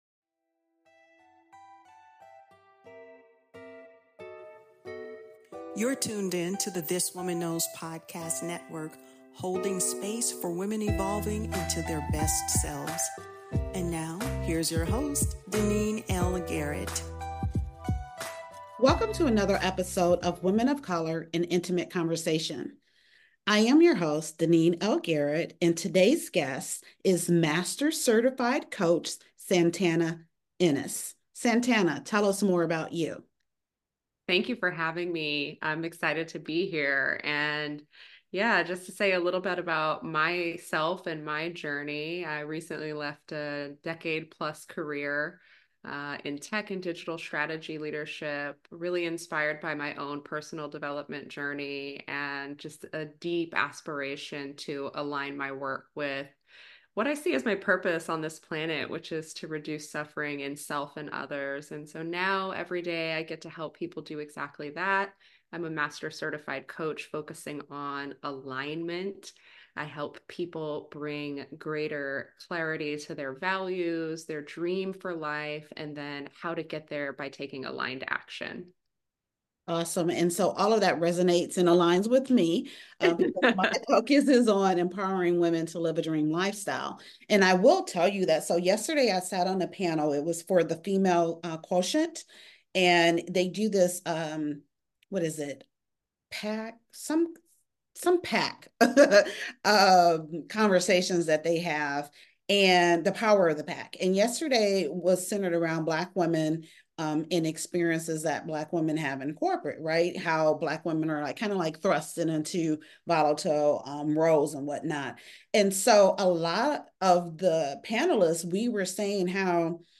An Intimate Conversation